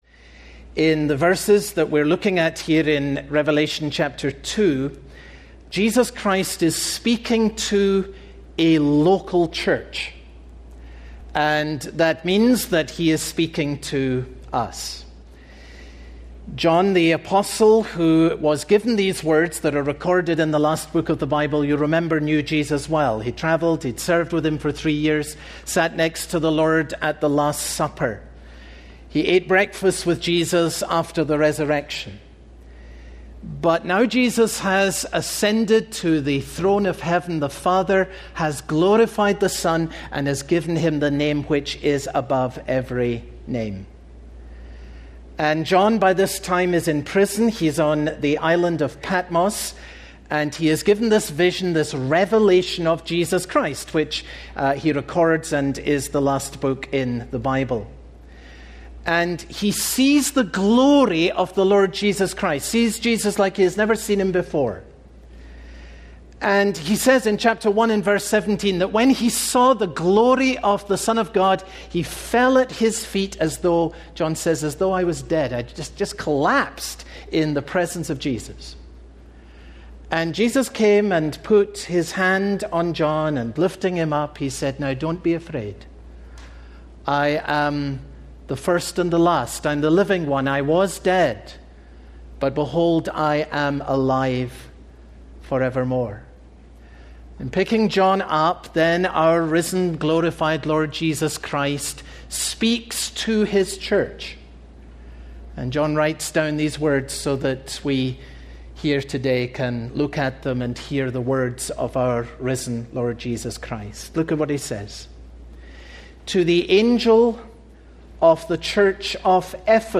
The Christian's First Love (Sermon on Revelation 2:1-7) - Open the Bible